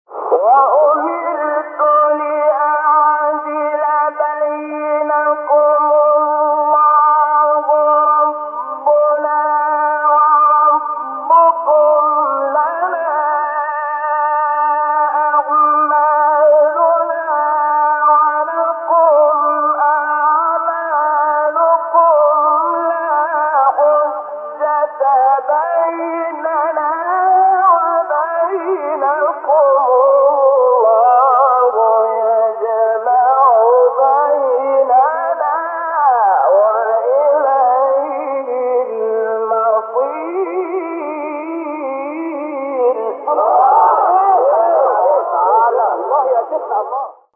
سوره : شوری آیه: 15 استاد : شحات محمد انور مقام : رست قبلی بعدی